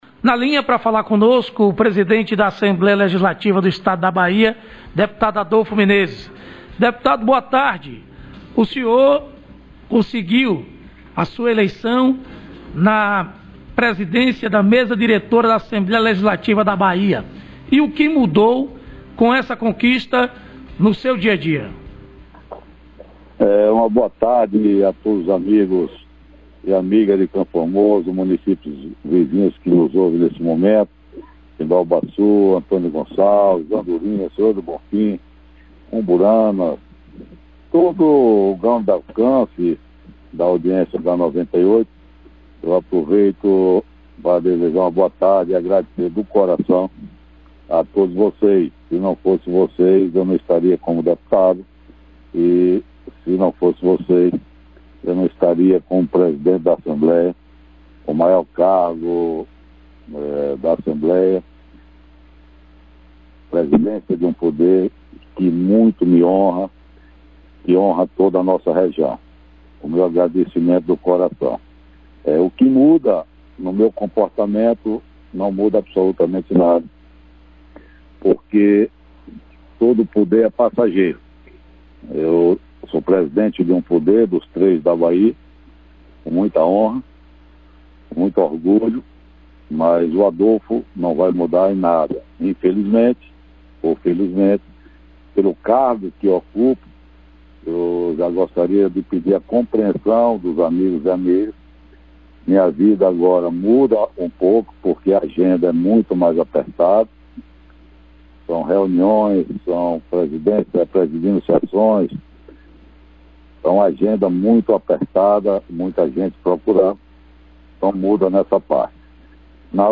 Entrevista: Presidente da ALBA Deputado Adolfo Menezes